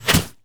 bullet_impact_snow_03.wav